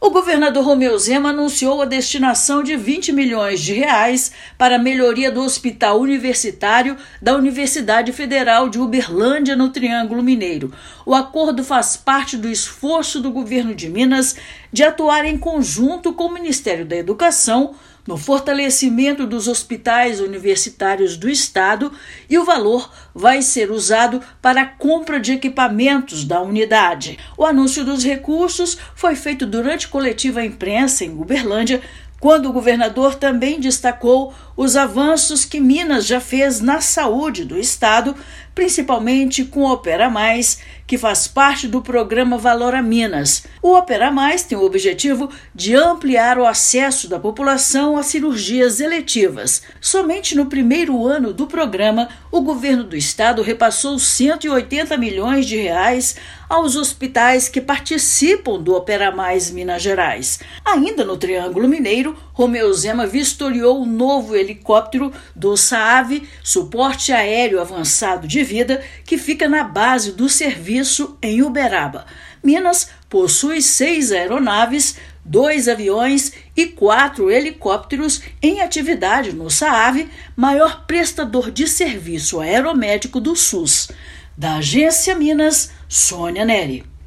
[RÁDIO] Governo de Minas vai destinar R$ 20 milhões para equipar Hospital Universitário em Uberlândia
Governador esteve na região, onde também vistoriou o novo helicóptero que vai auxiliar no atendimento do Samu, em Uberaba. Ouça matéria de rádio.